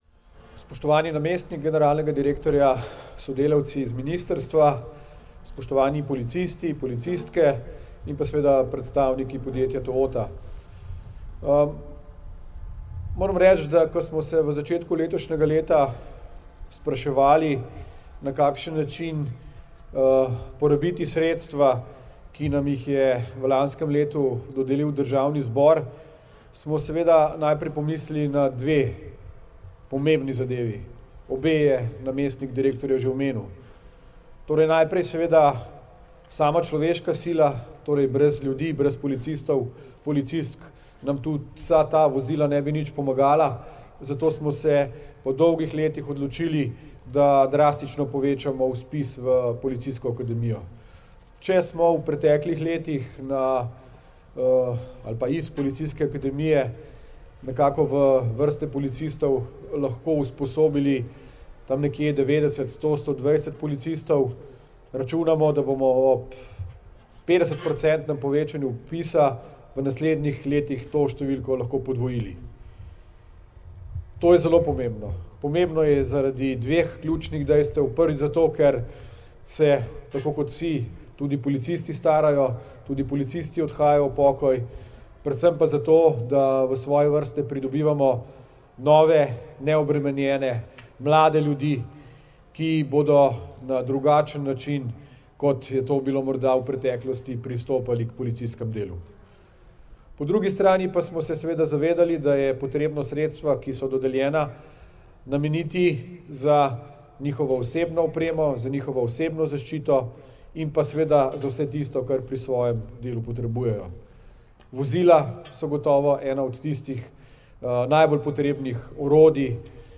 Namestnik generalnega direktorja policije Danijel Žibret in minister za notranje zadeve Aleš Hojs policistom sta danes, 16. novembra 2021, v Policijski akademiji v Ljubljani policistom predala nova službena vozila.
Zvočni posnetek izjave Aleša Hojsa